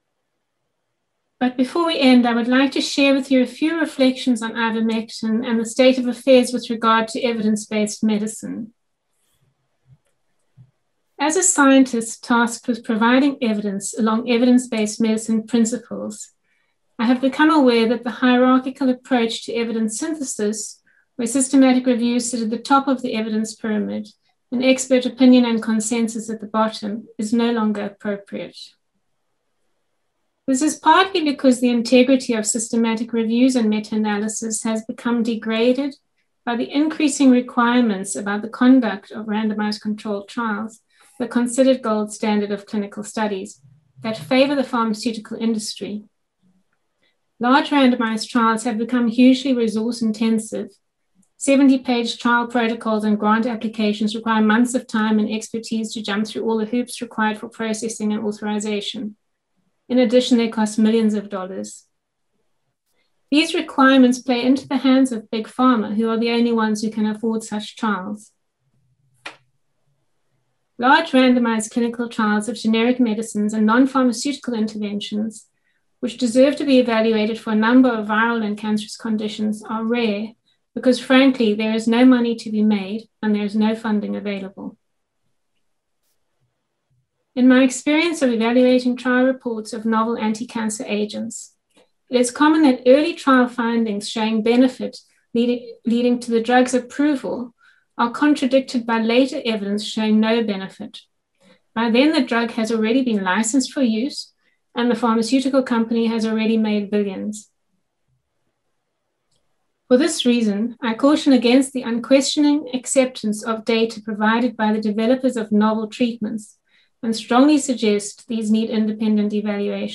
First International Ivermectin for Covid Conference, 25 April 2021
Concluding Speech